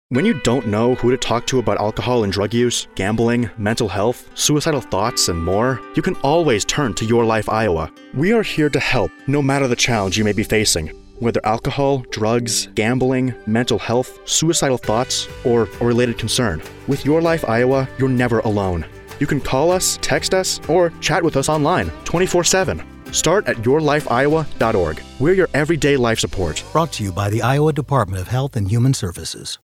:30 Radio Spot | YLI Awareness (Male-4)